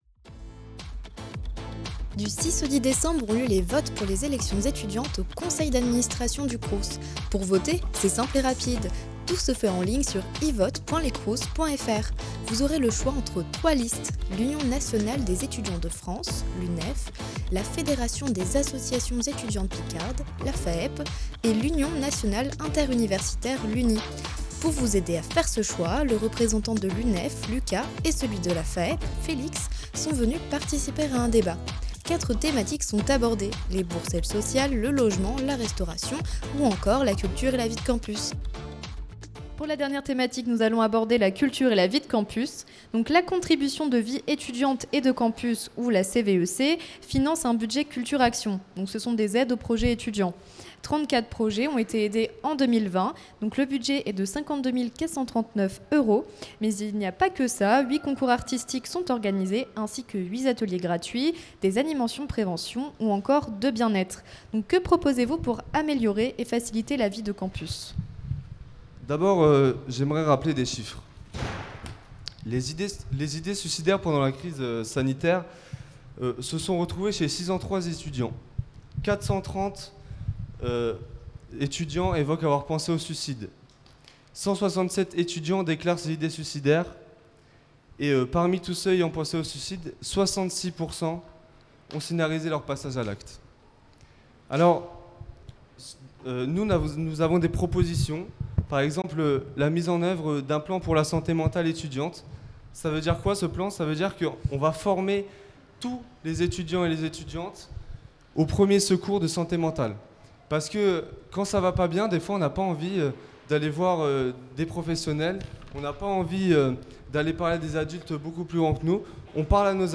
Suite au débat du 26 novembre, pour les élections étudiantes au conseil d’administration du CROUS Amiens Picardie , nous vous proposons d’écouter les différentes thématiques abordées !